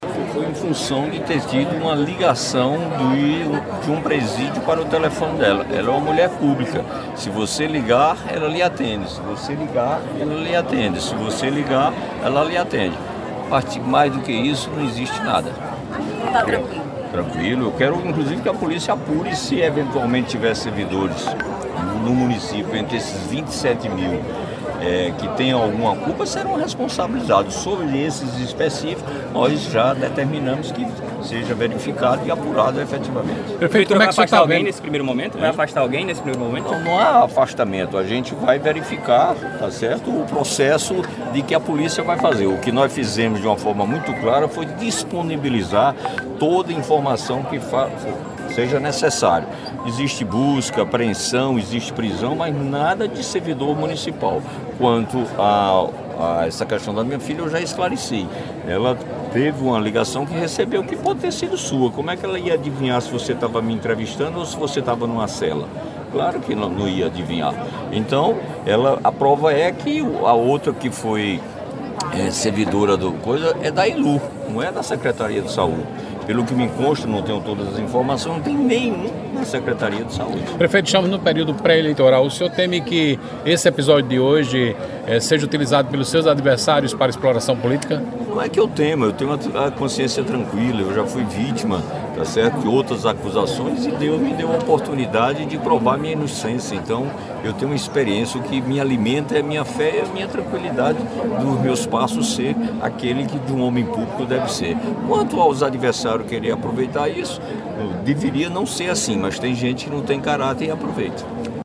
Os comentários do chefe do Executivo Municipal pessoense foram registrados pelo programa Correio Debate, da 98 FM, de João Pessoa, nesta sexta.